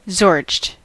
zorched: Wikimedia Commons US English Pronunciations
En-us-zorched.WAV